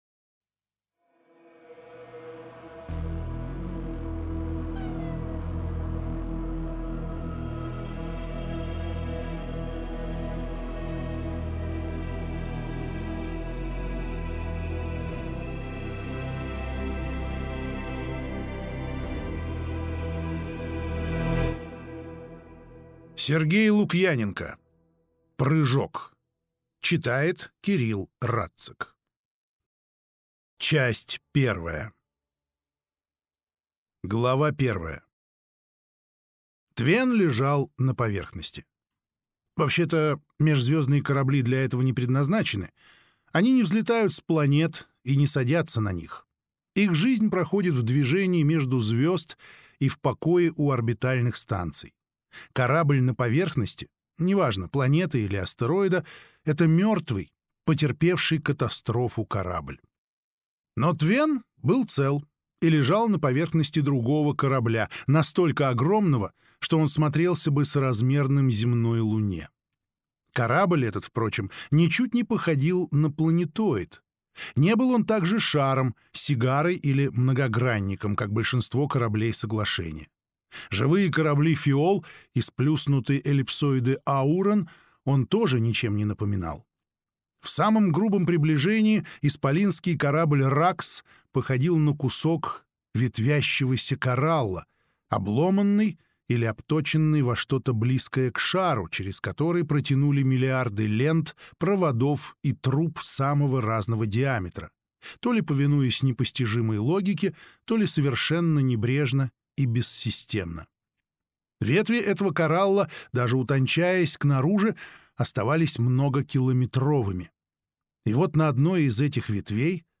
Аудиокнига Прыжок | Библиотека аудиокниг